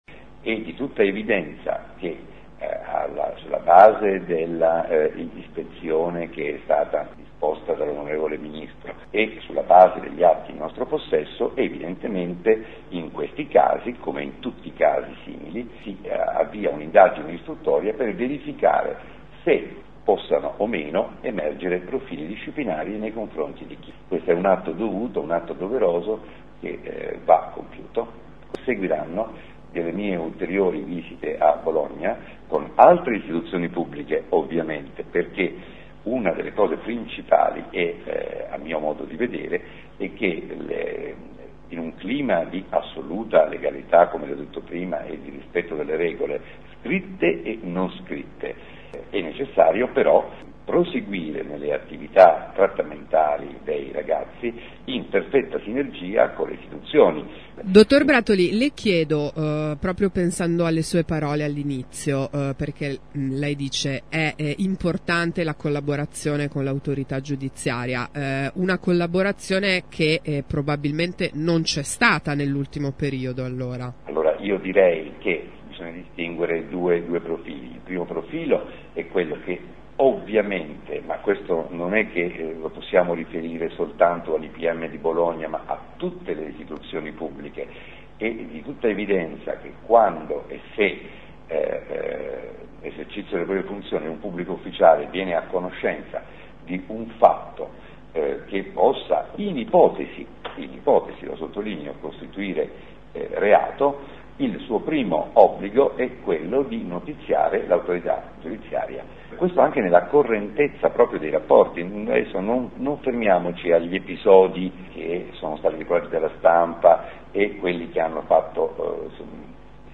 In una lunga intervista con Radio Città del Capo il numero uno del Dipartimento della Giustizia Minorile Bruno Brattoli fa il punto sulla bufera che si è abbattuta sul carcere del Pratello.